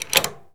pgs/Assets/Audio/Doors/door_lock_turn_01.wav
door_lock_turn_01.wav